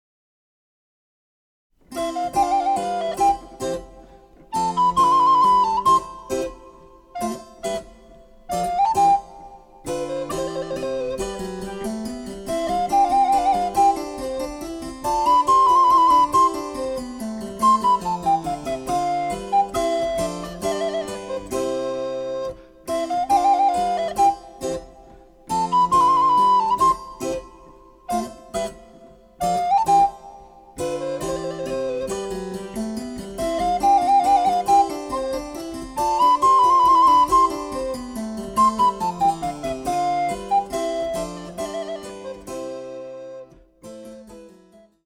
名作ソナタを生楽器によるチェンバロ伴奏CDで演奏できる！
★アルトリコーダー用の名曲をチェンバロ伴奏つきで演奏できる、「チェンバロ伴奏ＣＤブック」です。
★チェンバロ伴奏の演奏にはRJPが保有するチェンバロ（スピネット）を使っています。
(1)各楽章につきモダンピッチ(A=440Hz)の伴奏
(2)各楽章につきリコーダーの演奏を合わせた演奏例